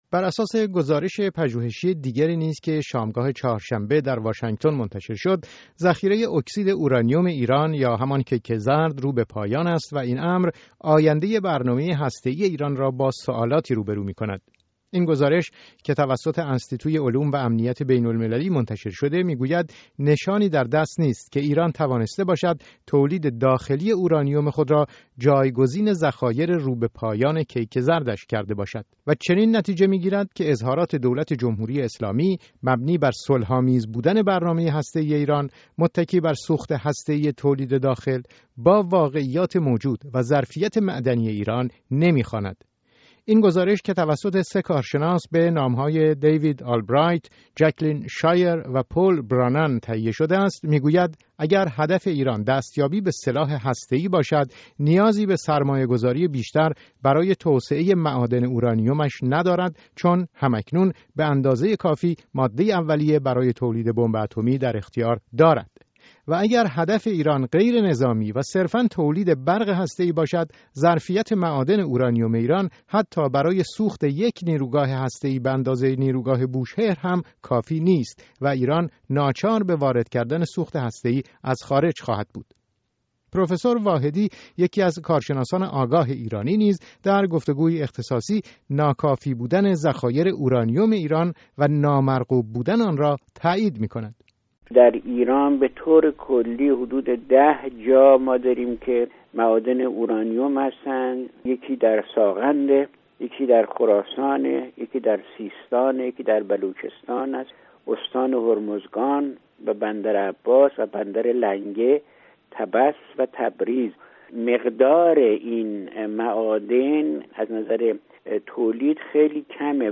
گزارش رادیویی در همین زمینه